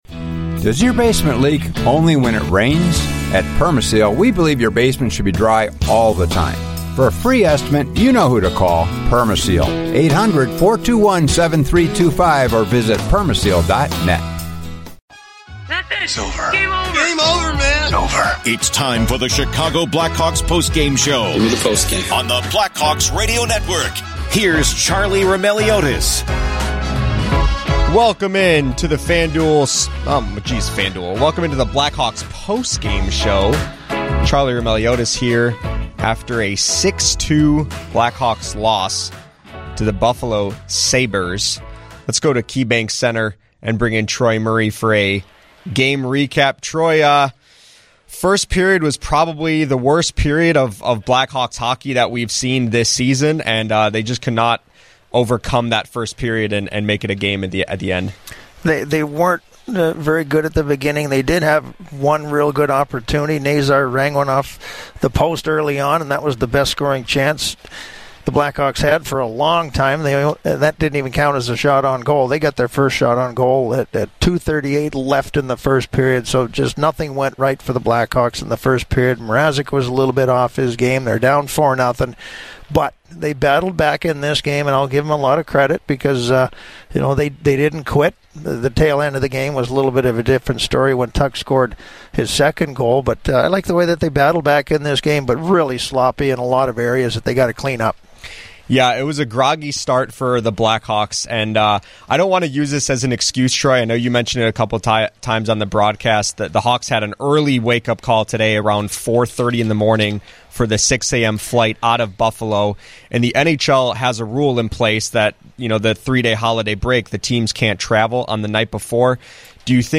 Troy Murray joins the discussion from KeyBank Center to talk about Chicago’s disastrous first period.
Later in the show, Seth Jones, Taylor Hall and interim head coach Anders Sorensen share their thoughts on the loss.